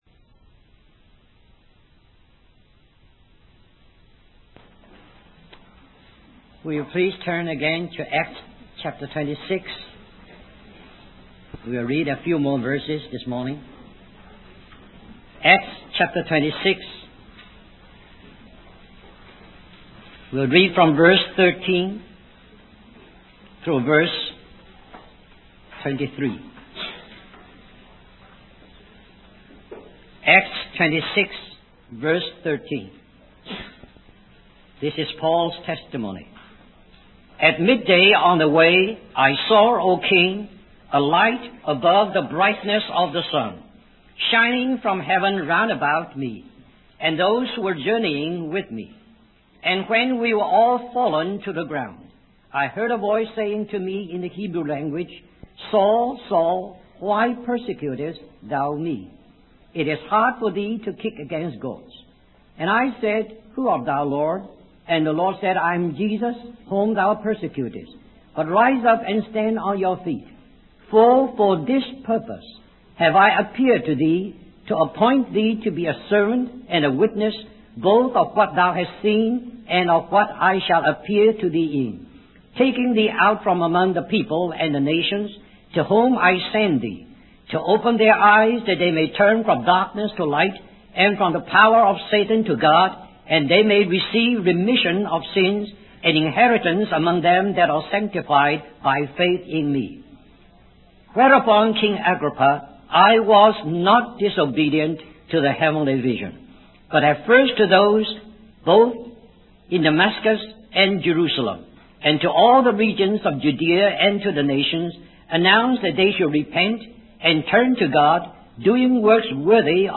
In this sermon, the preacher discusses the terrible state of a young man named Jacob who felt unworthy and far from God's vision for his life. However, God revealed Himself to Jacob through a vision of a ladder connecting heaven and earth, with angels ascending and descending.